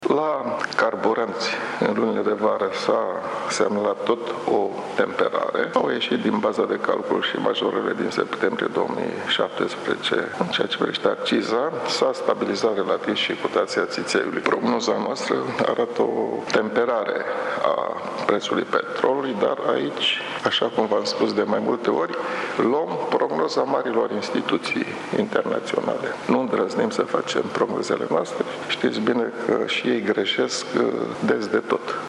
Mugur Isărescu, Guvernatorul BNR, a precizat, la prezentarea „Raportul asupra inflaţiei”, ediţia noiembrie 2018, că prognoza asupra inflaţiei anunţată joi ar putea fi influenţată cel mai mult, în perioada următoare, de evoluţia preţul petrolului, care se îndreaptă spre 70 dolari pe baril.